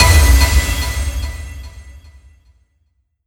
confirm-selection.wav